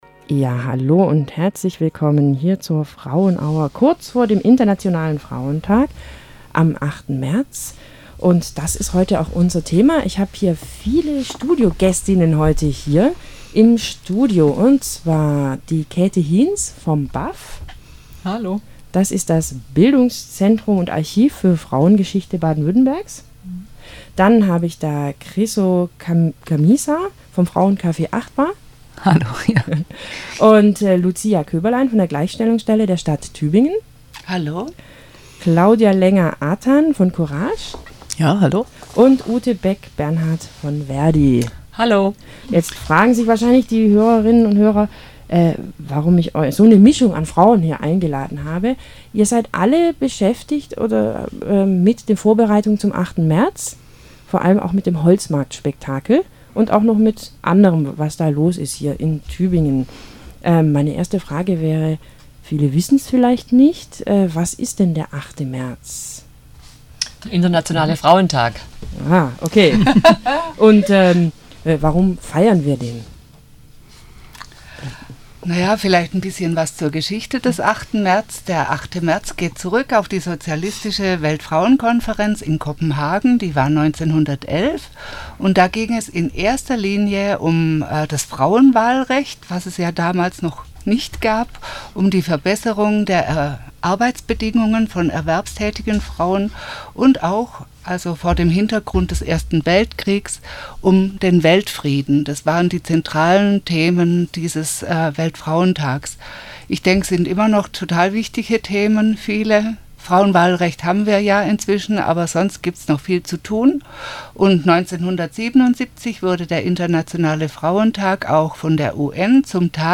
Frauen verschiedener Frauengruppen aus Tübingen sind am 8. März von 9 -10 Uhr bei der Wüsten Welle zu hören. Sie informieren über ihre Aktionen auf dem Holzmarkt am Internationalen Frauentag.